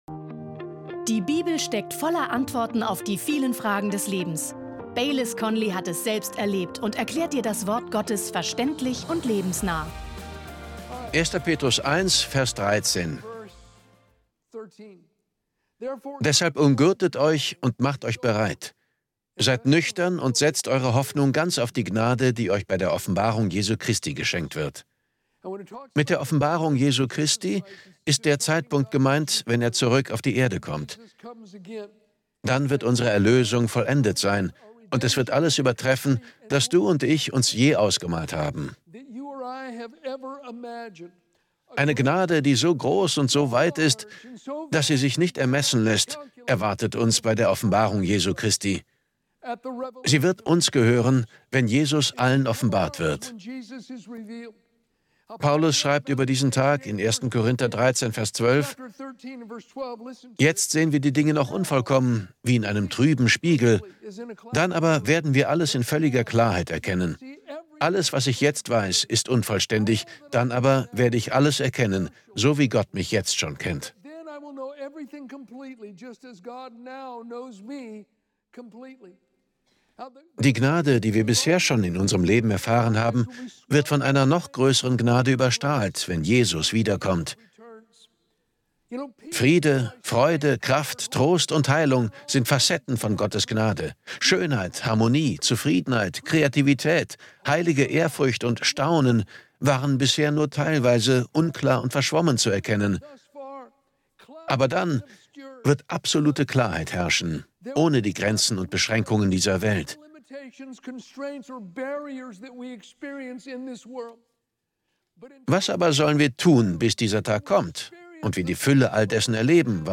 Beschreibung vor 3 Monaten Wenn sich dein Denken ändert, verändert sich dein Leben. In seiner Predigt zum 1.